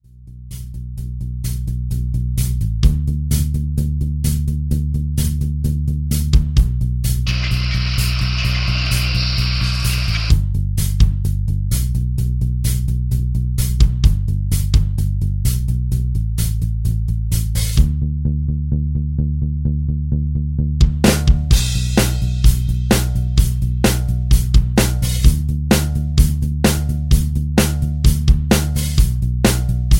Backing track files: 2000s (3150)